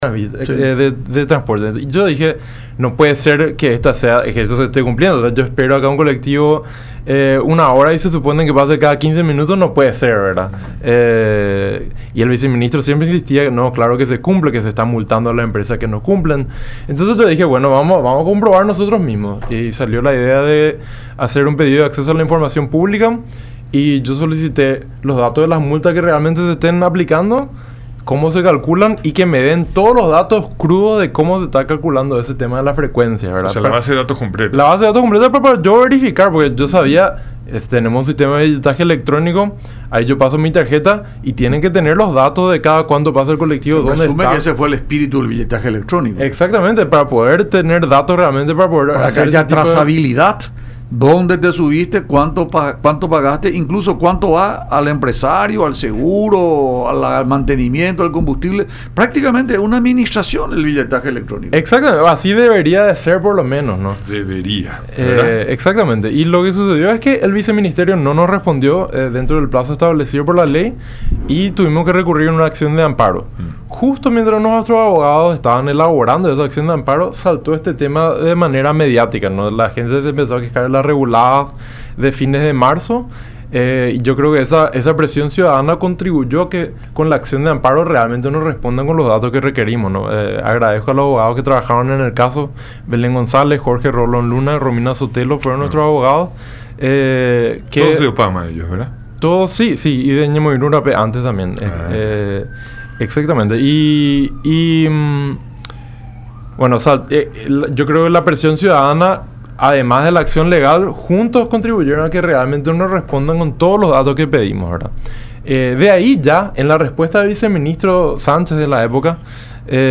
estudios de Unión TV y radio La Unión durante el programa Francamente